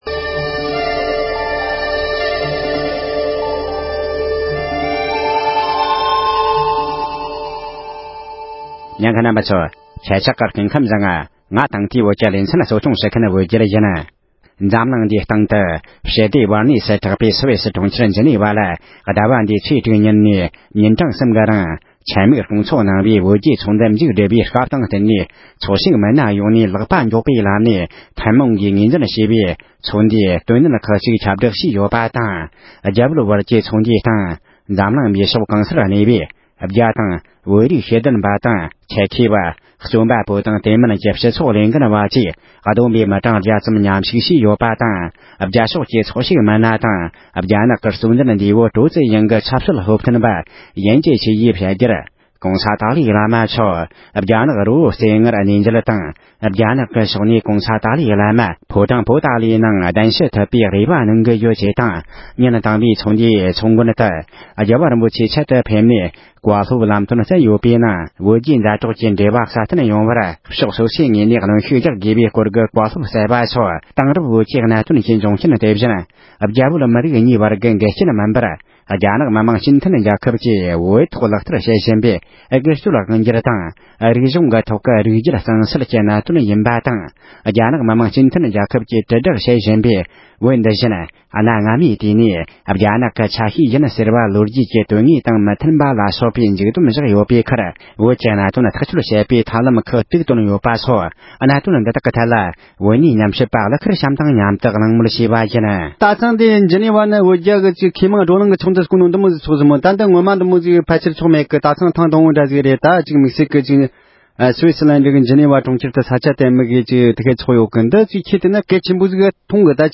ཉེ་ཆར་སུའི་སི་གྲོང་ཁྱེར་ཇི་ནི་བ་ནང་བསྐོང་ཚོགས་གནང་བའི་རྒྱལ་སྤྱིའི་བོད་རྒྱ་འབྲེལ་མོལ་ལྷན་ཚོགས་དང་འབྲེལ་བའི་གླེང་མོལ།